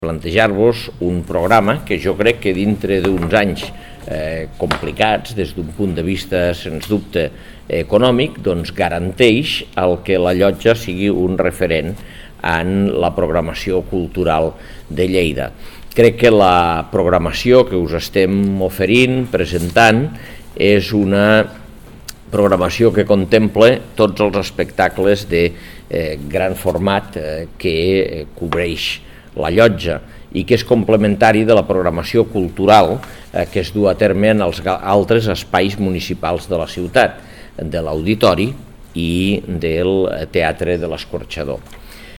Inclou arxiu de so d'Àngel Ros.